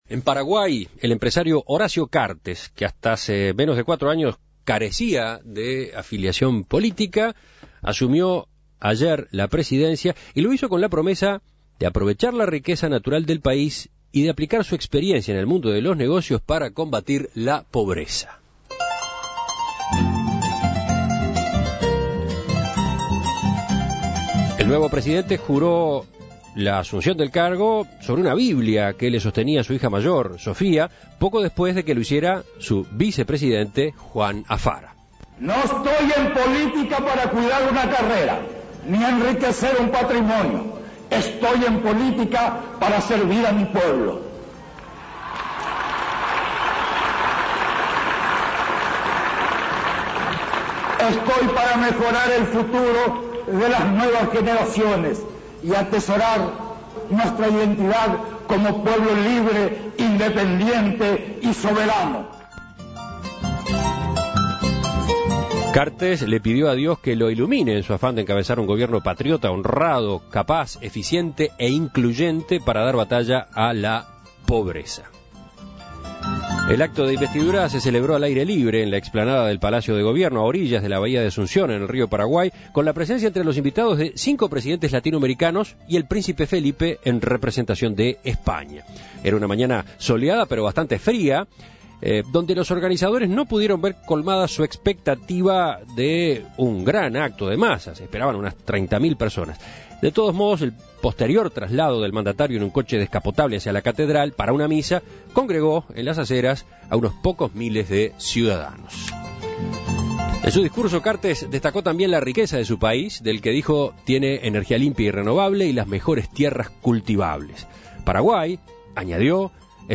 periodista uruguayo radicado en Paraguay.